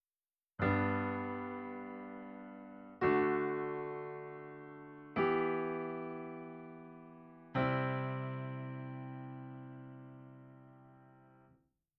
I should make the distinction here that when this chord pattern occurs in Avril Lavigne’s songs, it begins on the 6th or submediant scale degree:
||: vi IV | I V : ||